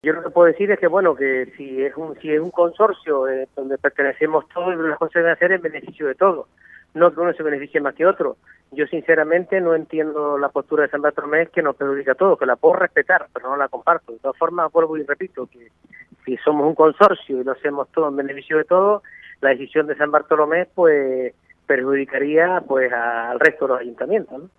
En este sentido, además de Echedey Eugenio, el alcalde de Teguise, Oswaldo Betancort, el de Haría, Marci Acuña, y el de Tinajo, Jesús Machín han expuesto en Crónicas Radio.Cope Lanzarote que consideran que la única opción para que estos proyectos no quiebren sin nacer "es plantear una derrama a los siete ayuntamientos que forman parte del Consorcio, incluido el de San Bartolomé".